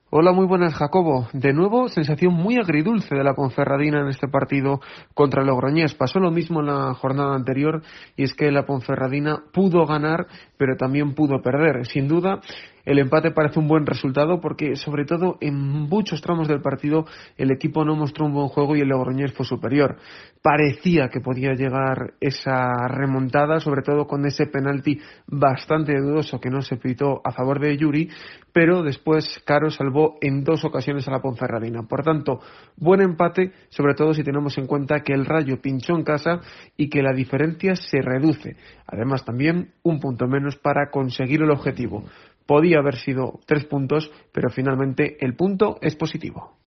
DCB-TIEMPO DE OPINIÓN